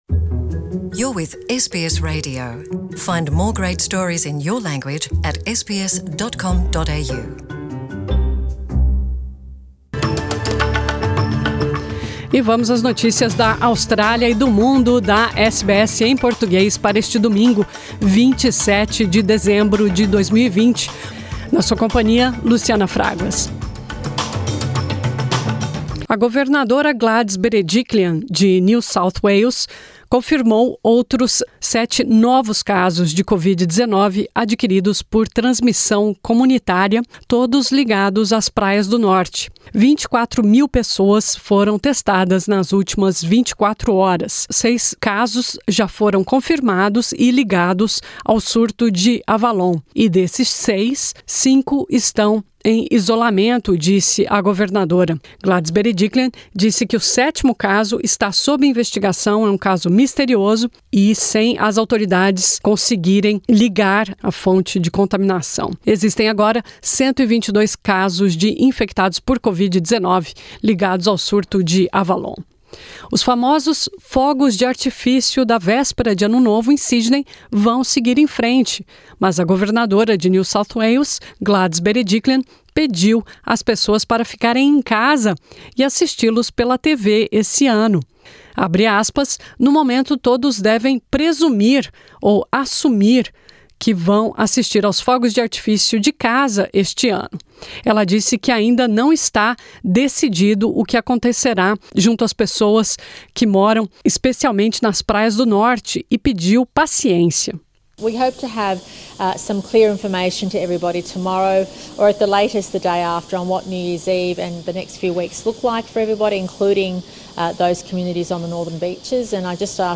Notícias da Austrália e do Mundo | SBS em Português | 27 de dezembro de 2020